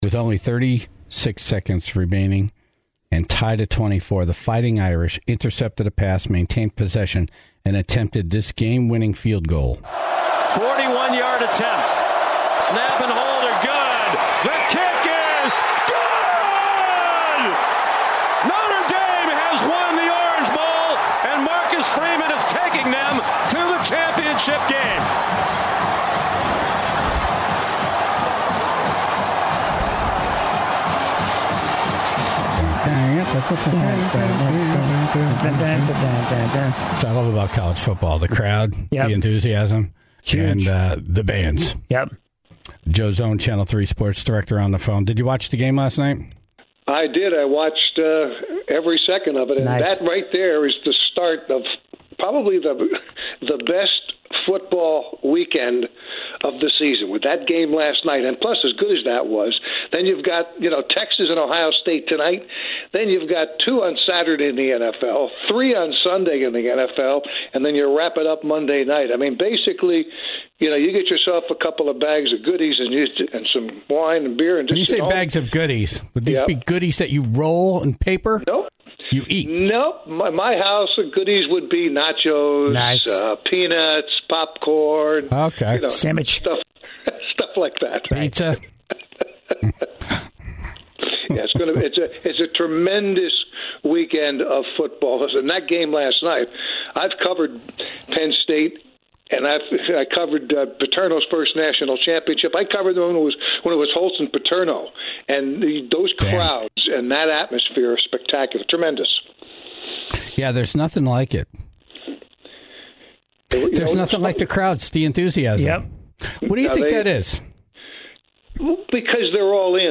Plus, the Tribe called in their stories of scars and injuries following some nights of partying.